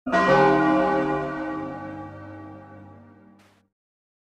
heartbeat.mp3